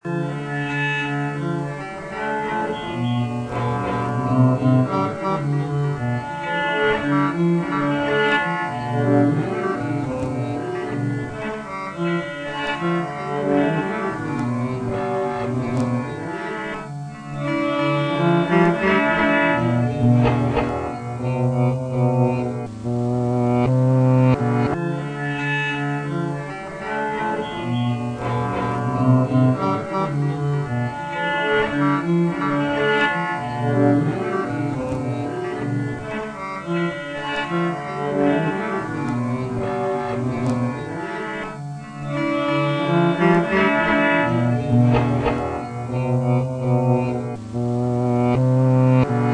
My Hari-Krishna Friends - I played something on my guitar, recorded it, then randomly reversed and cut and inverted the balance...